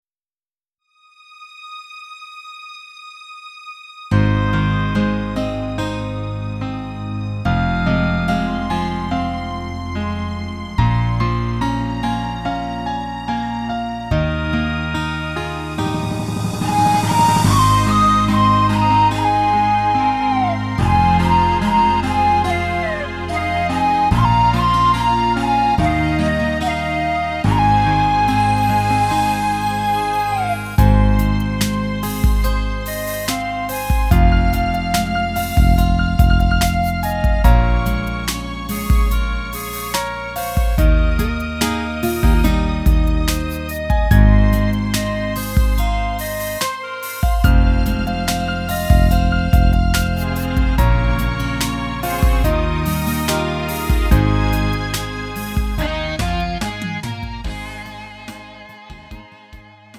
음정 원키 3:39
장르 구분 Lite MR